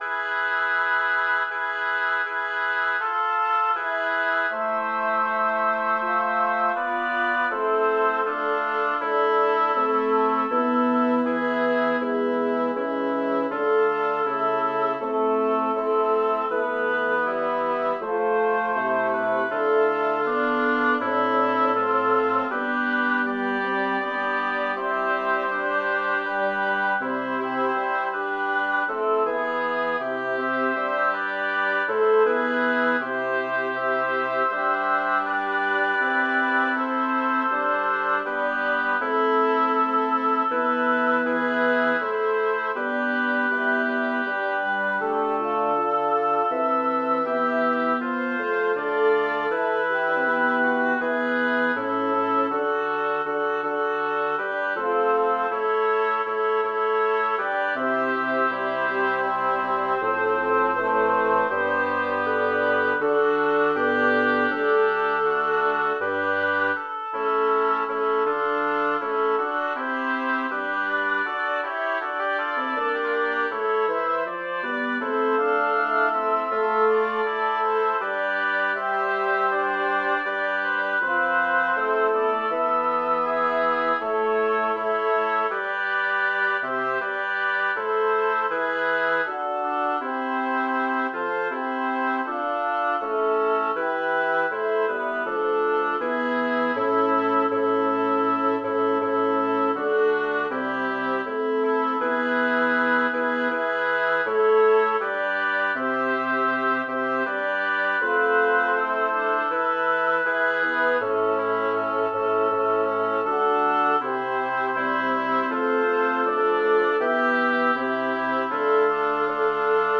Title: Aure che'l tristo e lamentevol suono Composer: Philippe de Monte Lyricist: Number of voices: 6vv Voicing: SAATTB Genre: Secular, Madrigal
Language: Italian Instruments: A cappella